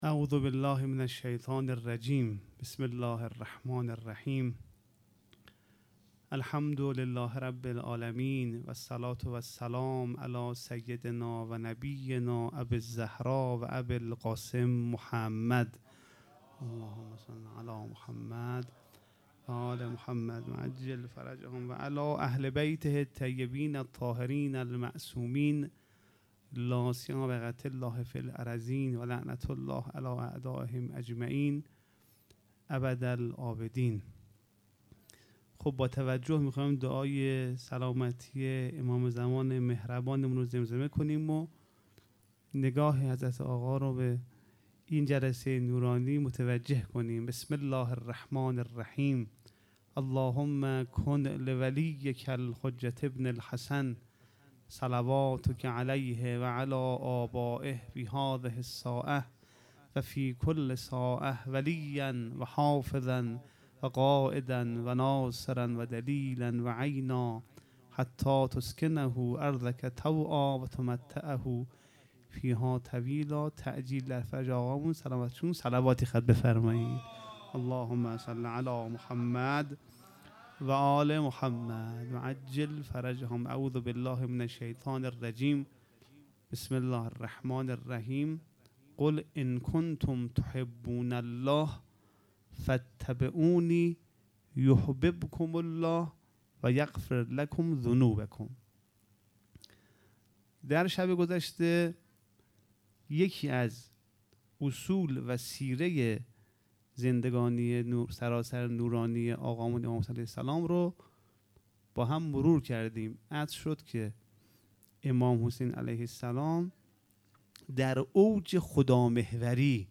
محرم ۱۴۴۵ _ شب سوم